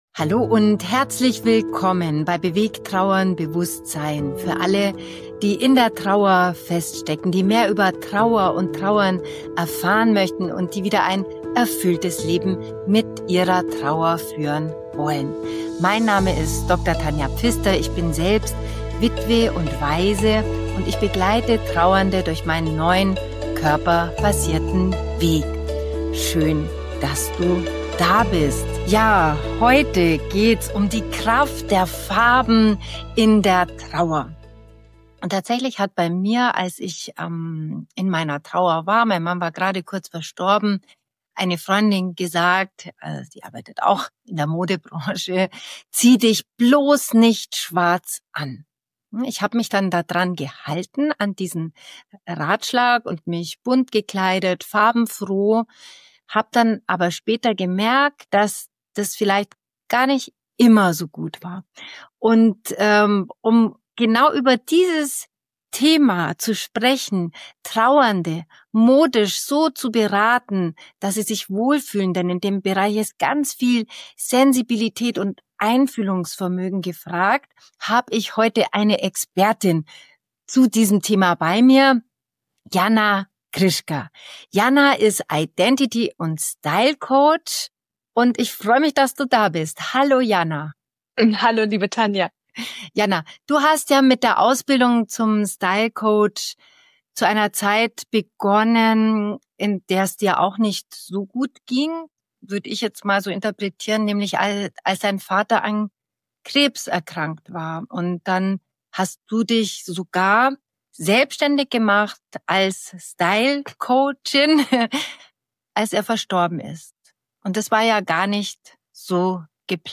#42 - Die Kraft von Farben in der Trauer. Ein Interview